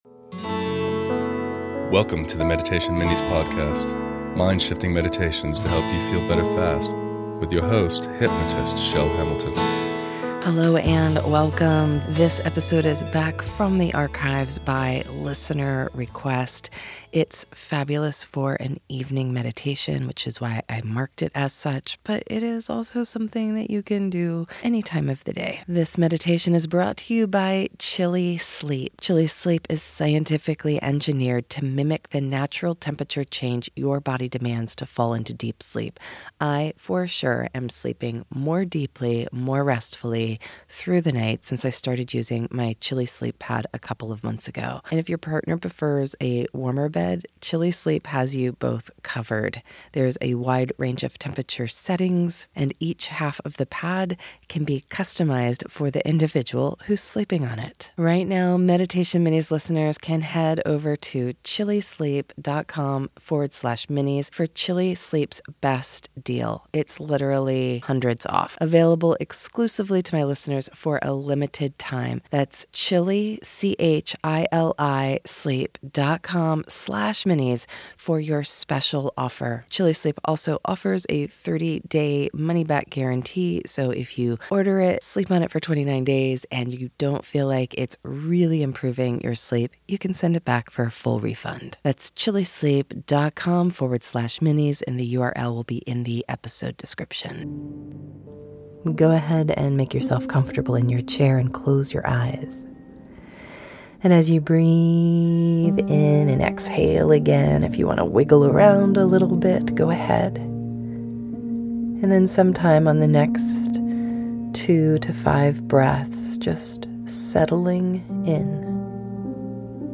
Overcome resentment and anger towards someone with the practice you will be guided through in this meditation.